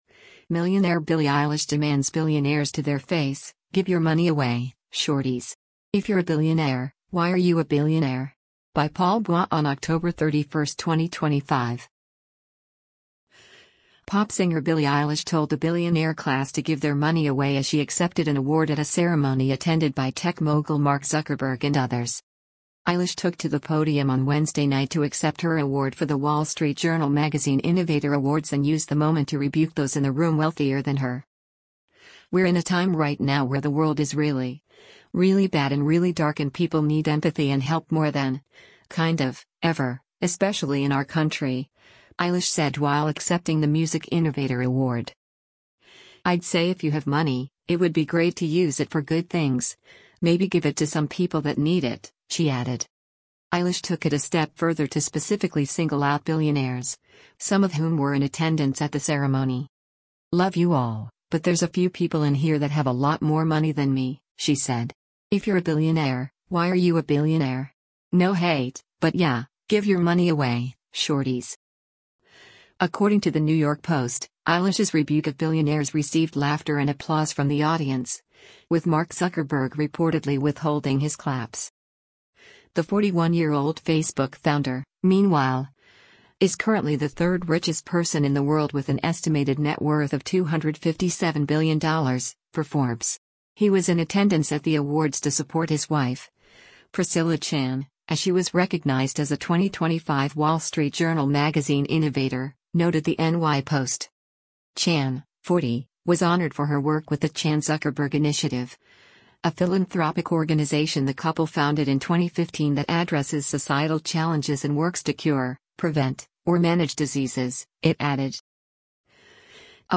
Pop singer Billie Eilish told the billionaire class to give their money away as she accepted an award at a ceremony attended by tech mogul Mark Zuckerberg and others.
Eilish took to the podium on Wednesday night to accept her award for the Wall Street Journal Magazine Innovator Awards and used the moment to rebuke those in the room wealthier than her.
According to the New York Post, Eilish’s rebuke of billionaires received “laughter and applause from the audience,” with Mark Zuckerberg reportedly withholding his claps.